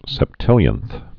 (sĕp-tĭlyənth)